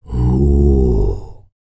TUVANGROAN06.wav